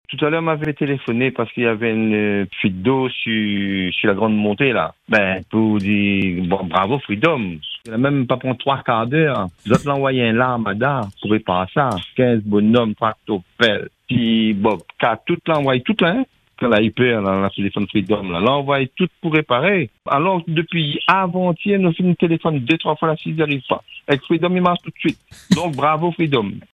Lassé d’attendre, l’habitant décide alors de contacter notre antenne pour témoigner de son ras-le-bol.